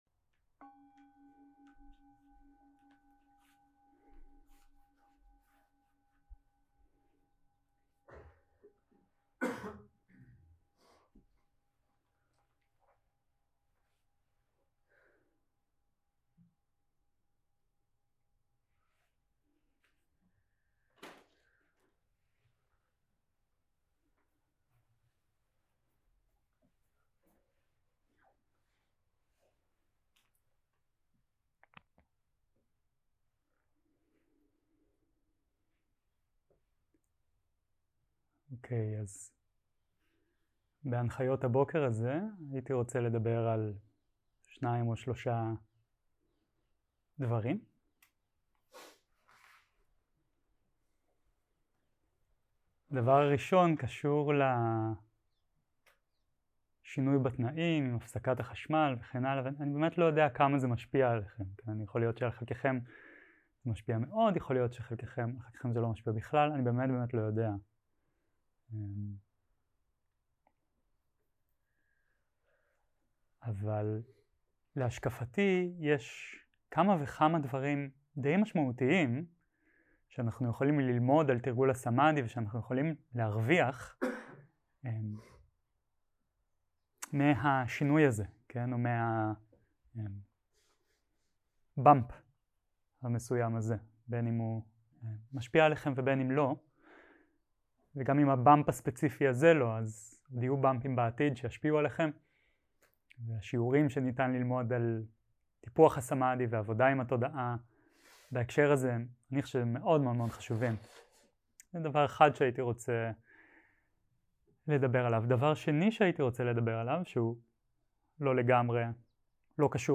יום 6 - הקלטה 9 - בוקר - הנחיות למדיטציה - התמודדות עם מכשולים, ארבעת בסיסי הכוח 0:00 0:00 סוג ההקלטה: Dharma type: Guided meditation שפת ההקלטה: Dharma talk language: Hebrew